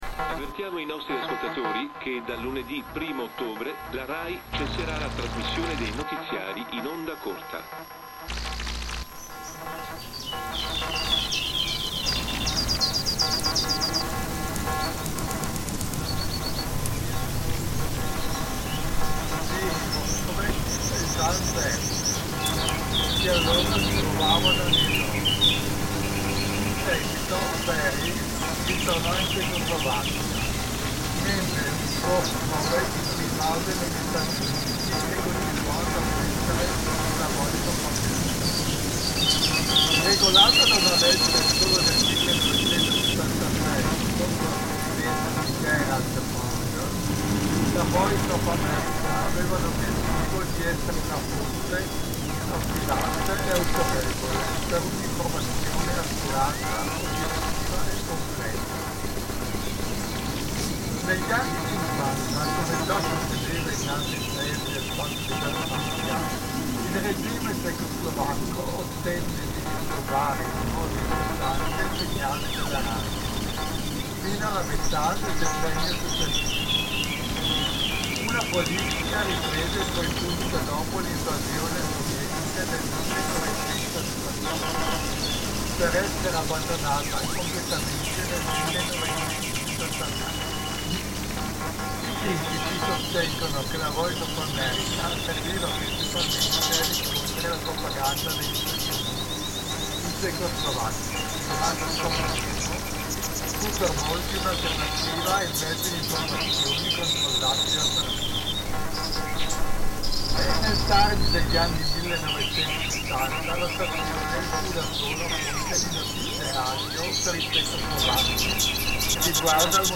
Birdsong on Monte Venda reimagined by Cities and Memory.
An idyllic setting near the top of Monte Venda, listening to birdsong in the sunshine.
This piece considers the limitations of human hearing, and that we only take a snapshot from the middle of the huge range of available sounds to hear. This piece reveals the hidden sound world of radio braodcasts, feedback, power lines and interference that are going on all around us unheard, even in the most ostensibly peaceful surroundings. Shortwave radio samples courtesy of The Shortwave Radio Archive, with thanks.